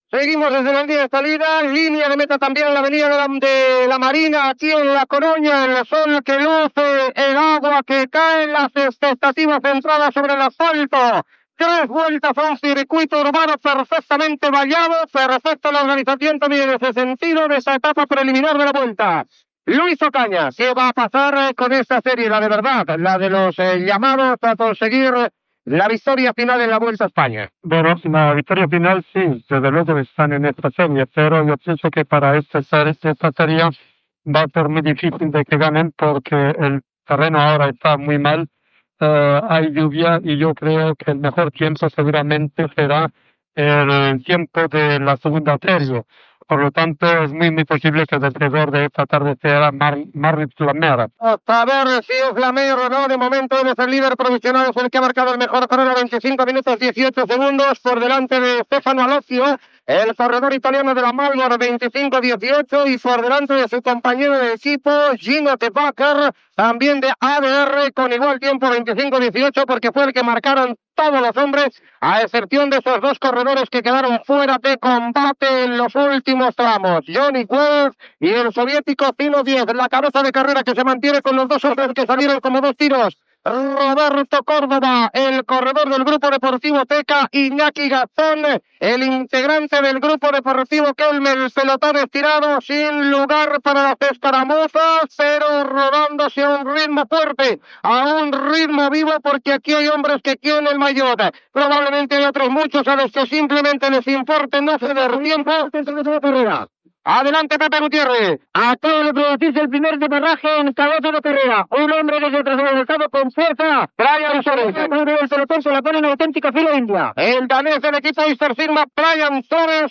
Narració de l'etapa pròleg de la Vuelta a España, que es es disputa a La Corunya.
Valoració de l'exciclista Luis Ocaña i narració de l'inici d'una escapada.
Esportiu